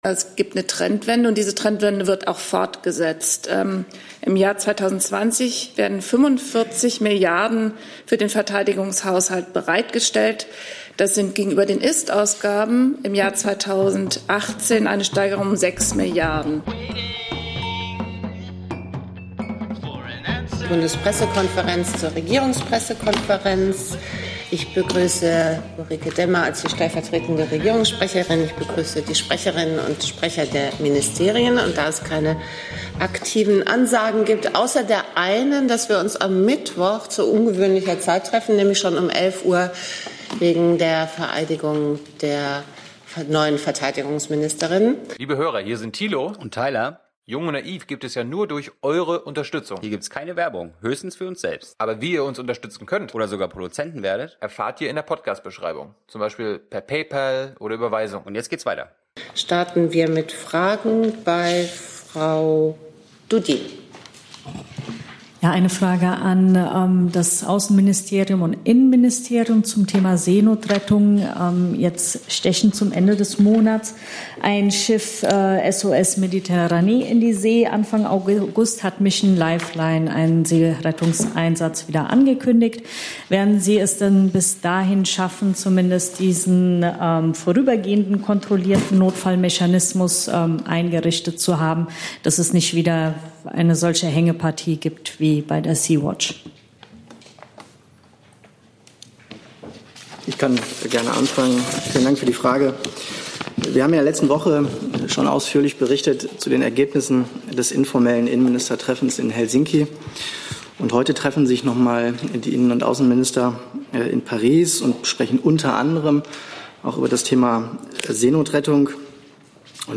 Regierungspressekonferenz in der Bundespressekonferenz Berlin, 22.07.2019 Themen: - Seenotrettung - Iran / Festgesetzte Tanker - NATO-Quote / Rüstungsetat - Klimaschutz - Wahlausgang Ukraine - Notfallversorgung - Venezuela - Batteriezellen-Forschung - HeidelbergCement / Israel / Besetzte Gebiete...